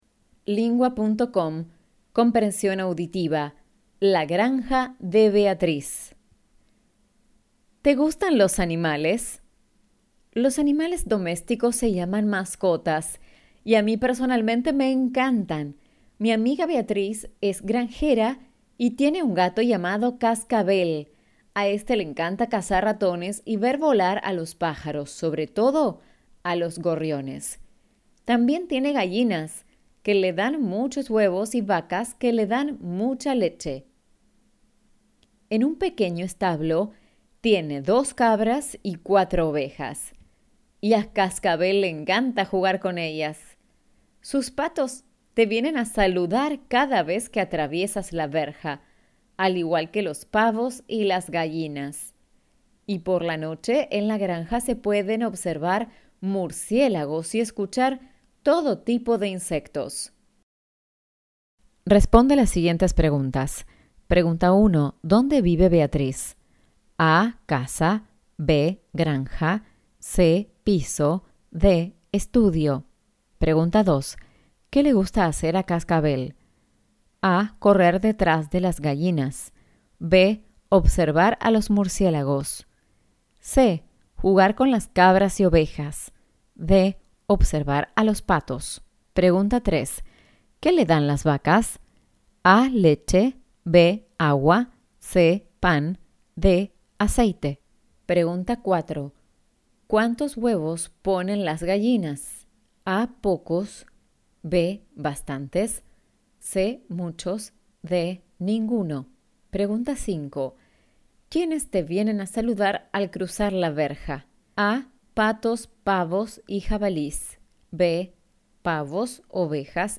Argentyna